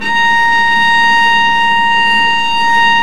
Index of /90_sSampleCDs/Roland L-CD702/VOL-1/STR_Vc Marc&Harm/STR_Vc Harmonics